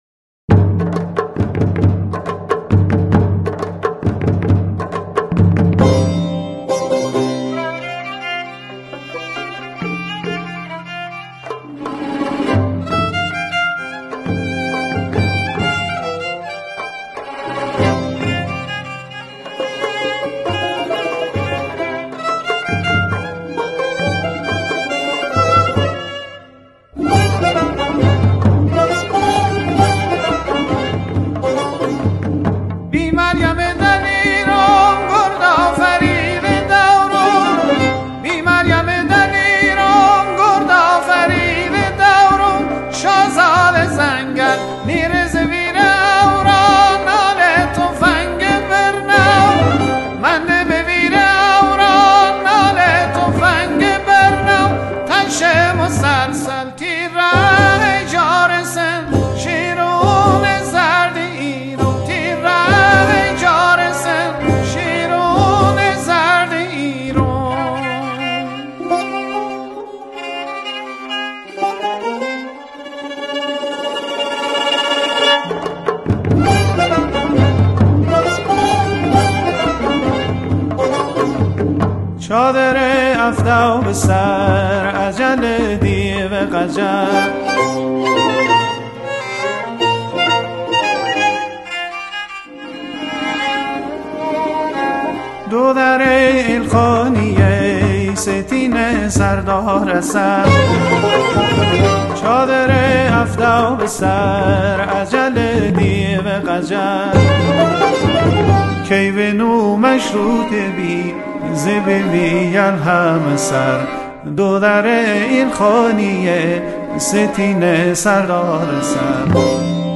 آهنگ بختیاری